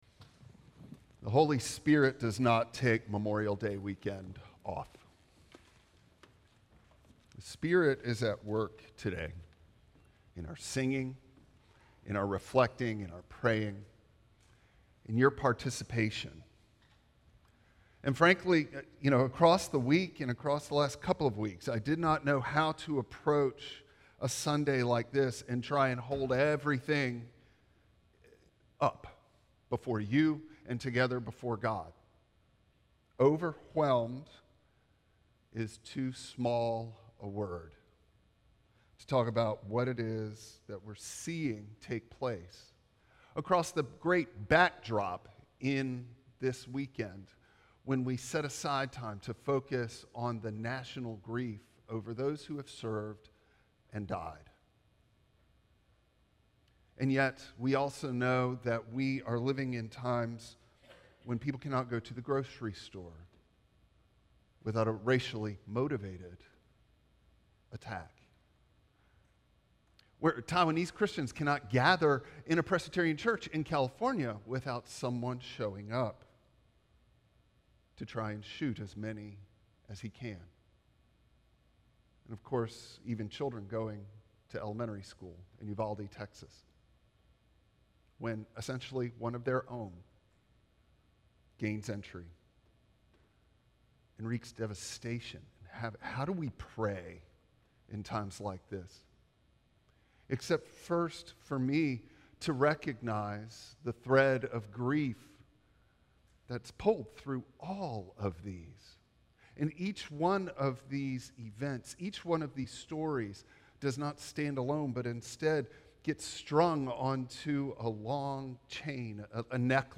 Passage: Matthew 28:16-20 Service Type: Traditional Service Bible Text